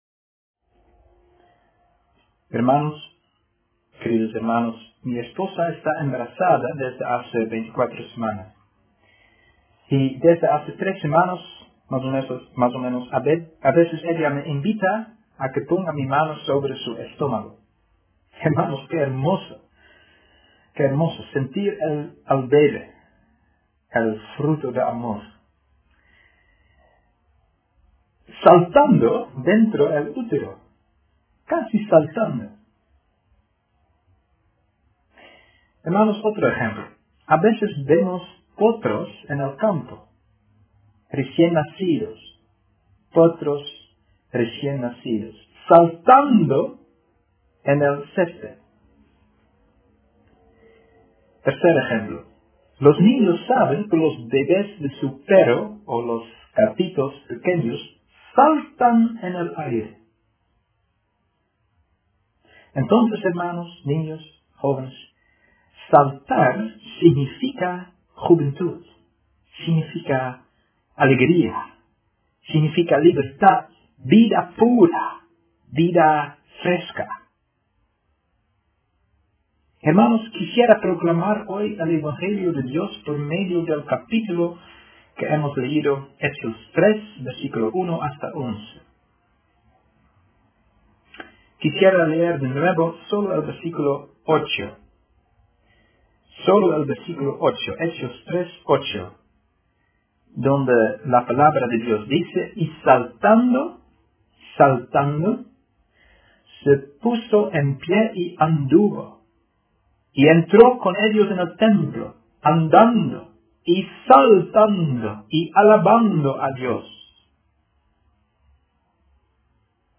Tipo: Sermón